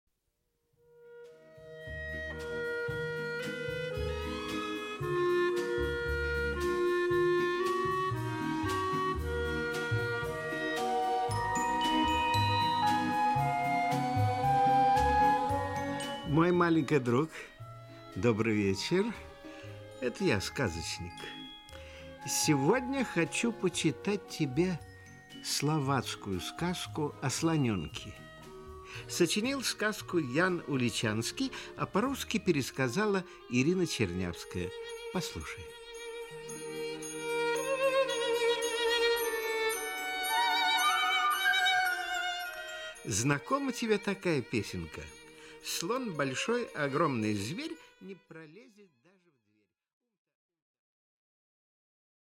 Aудиокнига О слоненке Автор Ян Уличанский Читает аудиокнигу Николай Литвинов.